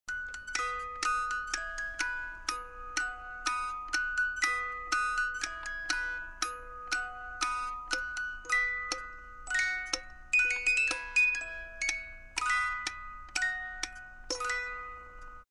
Пиликанье музыкальной шкатулки